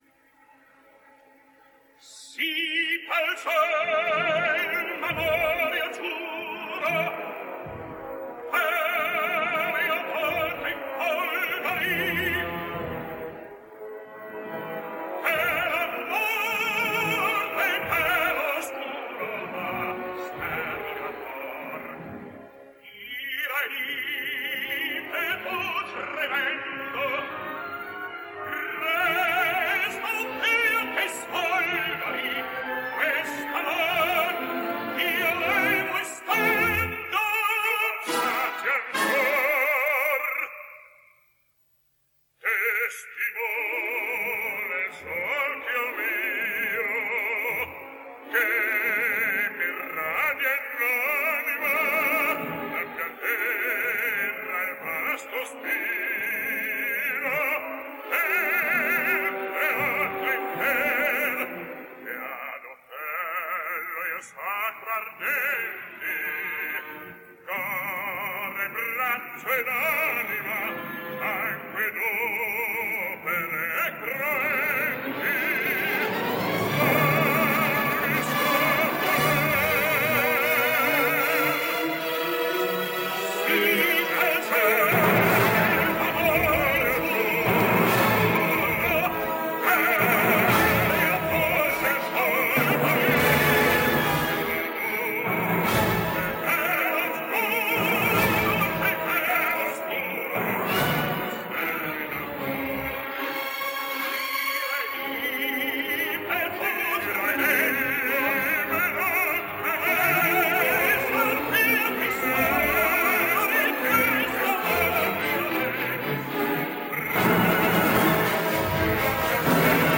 The Jago on this recording is the British baritone Peter Glossop, and it’s greatly to his credit, that he can keep his end up in partnership with quite such a Titanic, Otello as Jon Vickers.